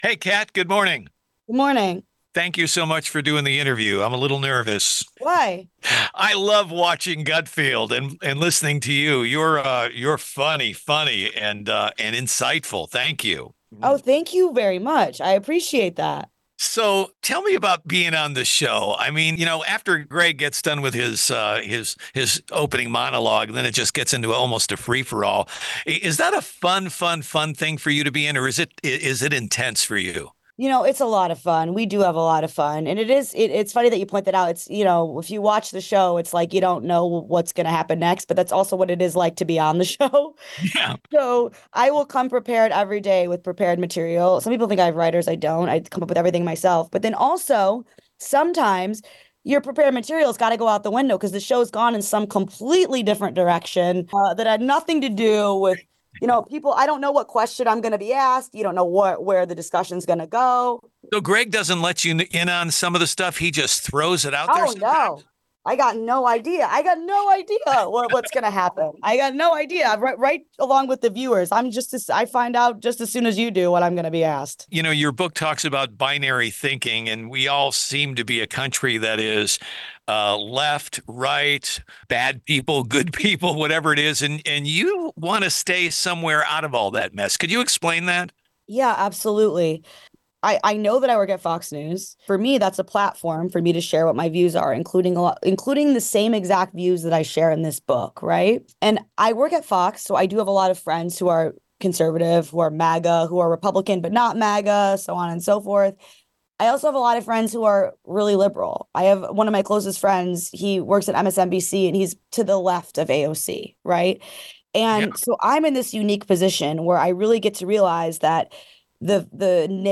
Interview with Kat Timph https